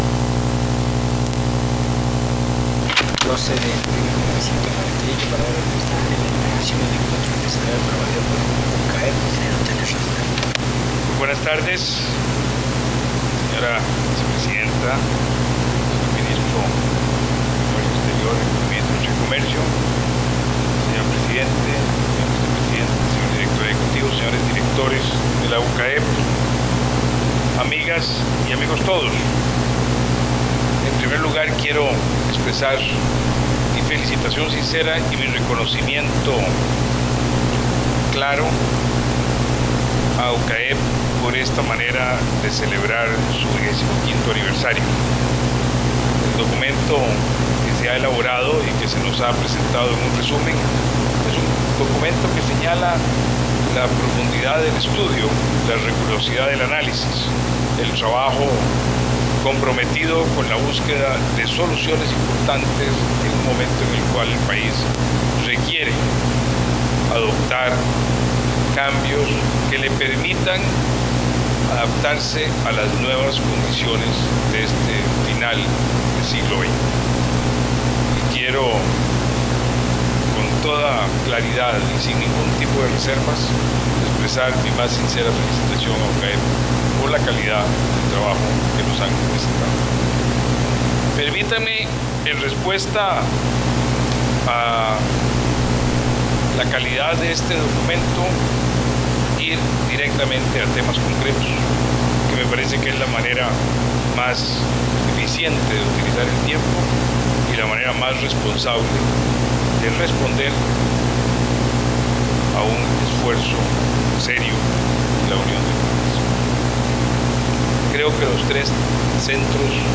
Notas: Casette de audio